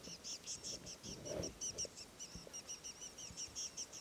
And this is a tawny-flanked prinia responding to a cuckoo finch in Zambia:
tawnyflankedprinia_wef.mp3